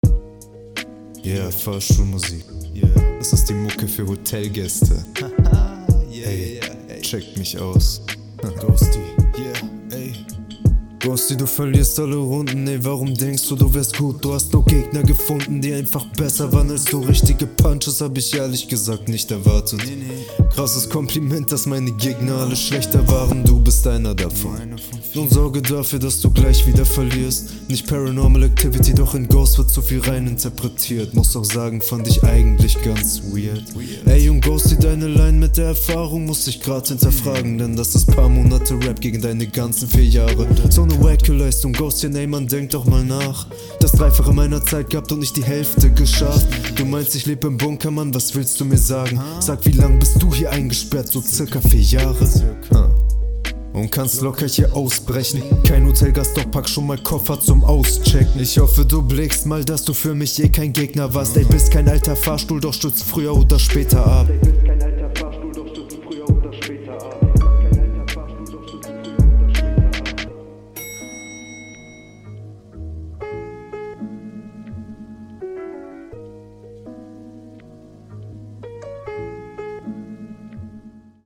Flow ist hier besser als in der HR2. Bist hier viel verständlicher …